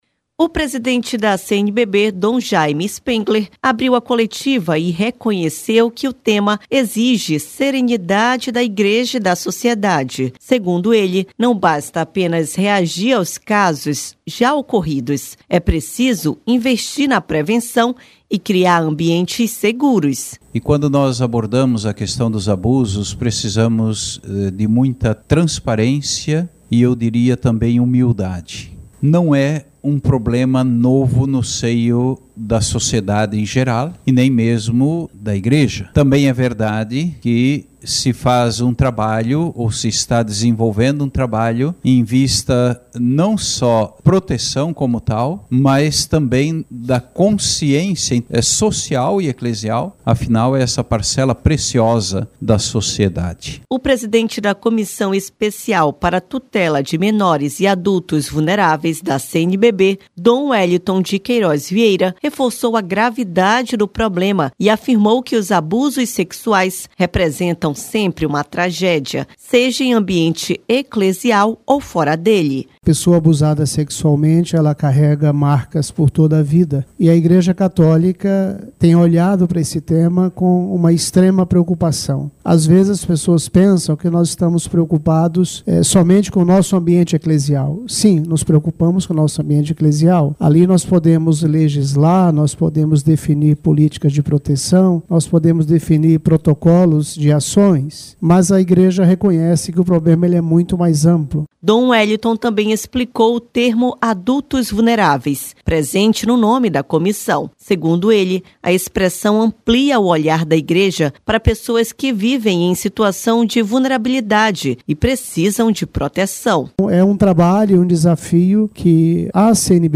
Em Aparecida, a CNBB dedicou uma das coletivas de imprensa ao debate sobre uma ferida para a Igreja e para a sociedade: os abusos contra menores e pessoas vulneráveis.